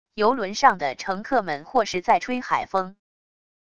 游轮上的乘客们或是在吹海风wav音频生成系统WAV Audio Player